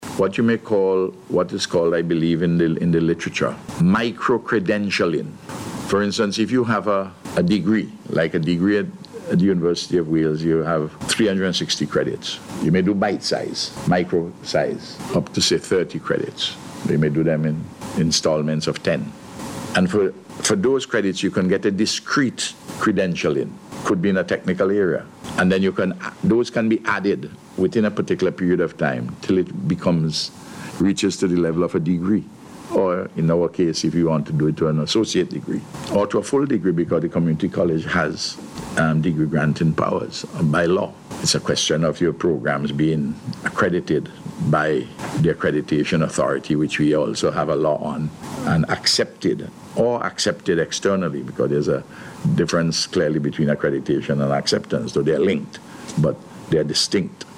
And he spoke of steps being taken to strengthen ties with the University, during a News Conference yesterday.